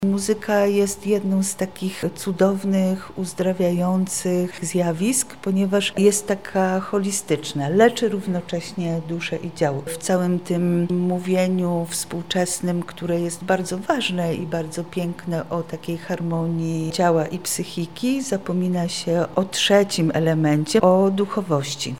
Spotkanie jest częścią cyklu dyskusji wokół oswajania traumy w sztuce.